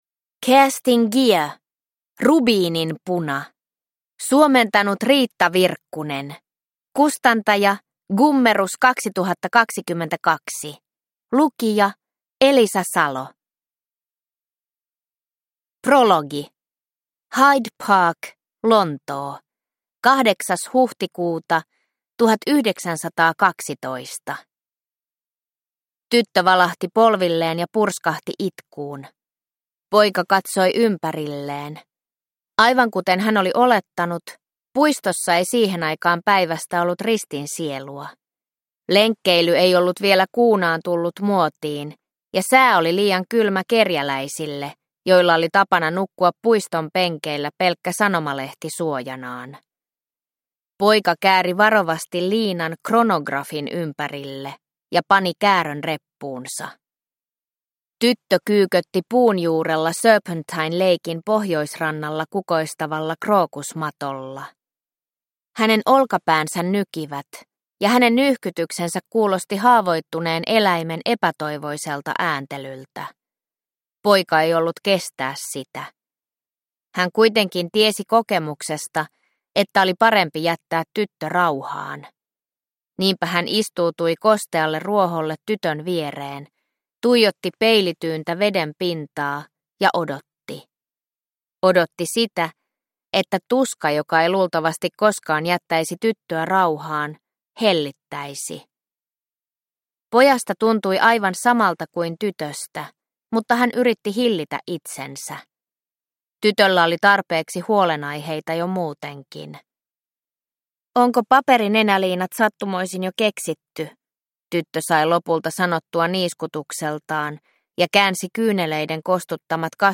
Rubiininpuna – Ljudbok – Laddas ner